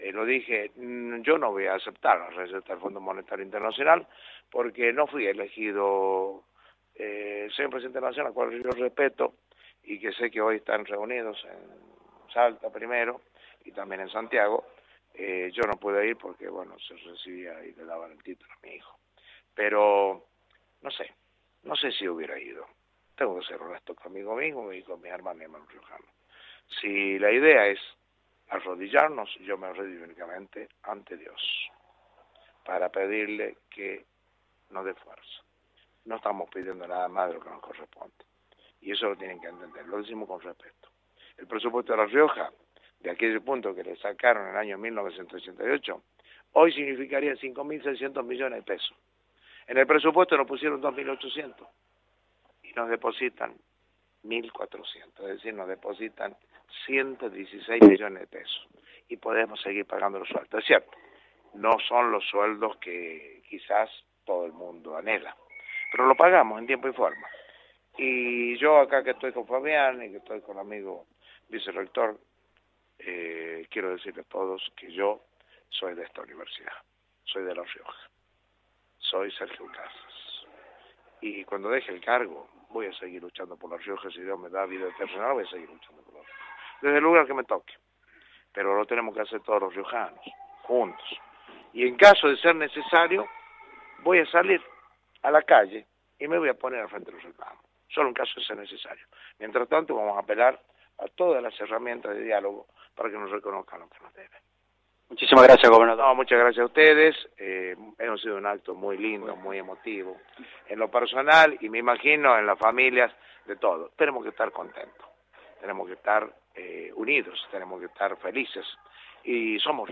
En declaraciones a Radio Universidad, el mandatario provincial aseguró: «Yo no voy a aceptar la receta del Fondo Monetario Internacional porque no fui elegido» para ello.
Casas dialogó con Radio Universidad por el FMI